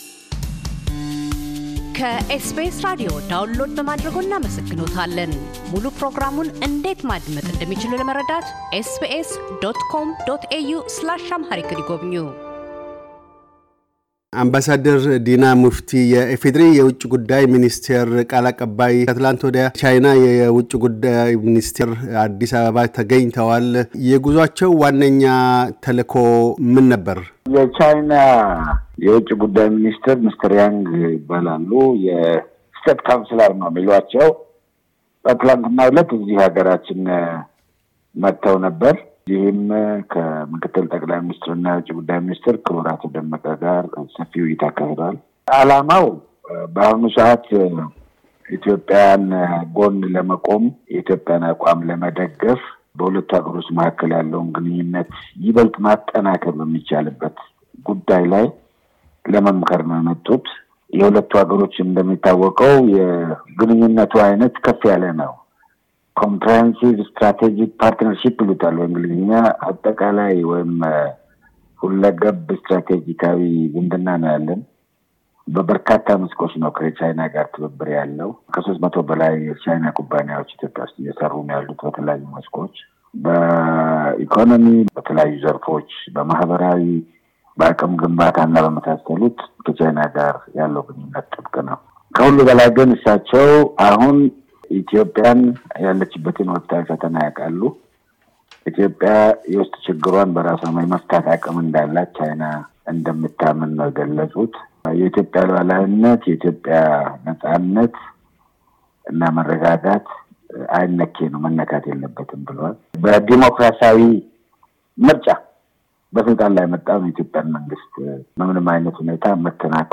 አምባሳደር ዲና ሙፍቲ - የኢፌዴሪ ውጭ ጉዳይ ሚኒስቴር ቃል አቀባይ፤ የሰሞኑን የቻይና ውጭ ጉዳይ ሚኒስትር የኢትዮጵያ ጉብኝት ዓላማና የኢትዮጵያና ዩናይትድ ስቴትስ ግንኙነት ደረጃን አስመልክተው ይናገራሉ።